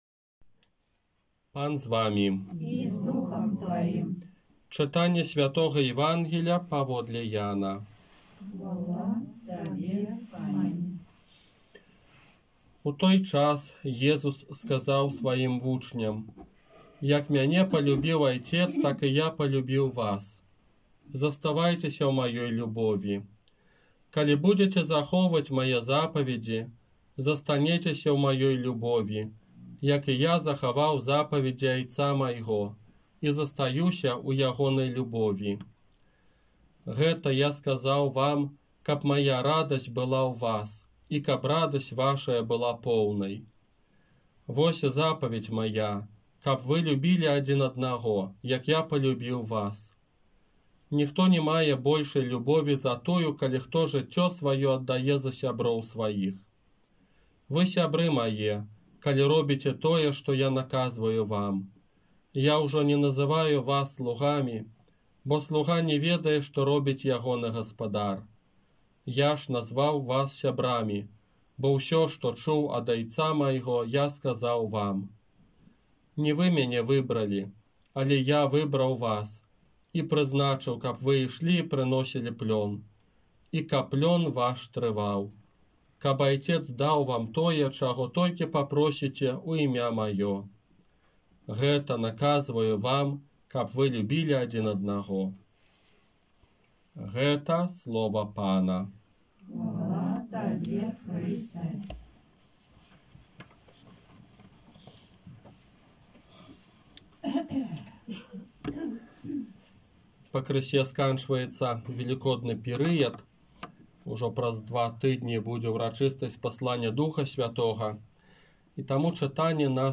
ОРША - ПАРАФІЯ СВЯТОГА ЯЗЭПА
Казанне на шостую Велікодную нядзелю